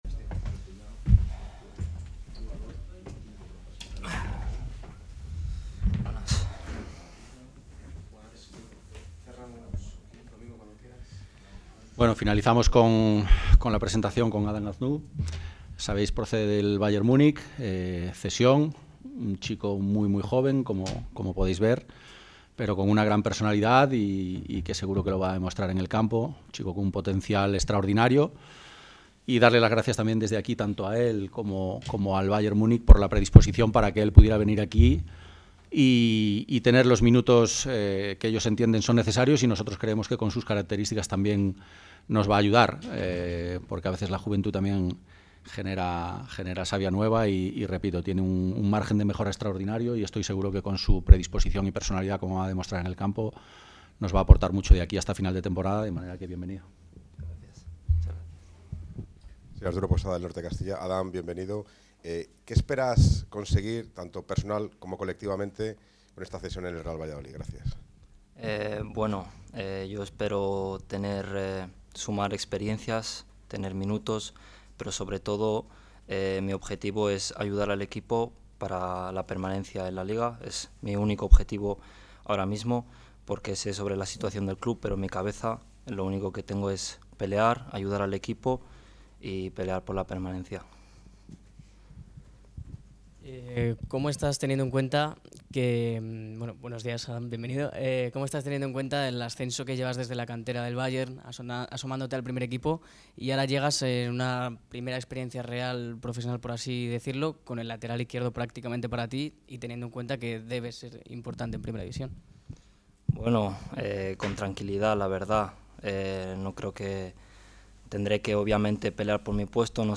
Ruedas de prensa
La sala de prensa del Estadio José Zorrilla albergó en la mañana de este miércoles la cuádruple presentación de los últimos refuerzos del Real Valladolid en el mercado invernal.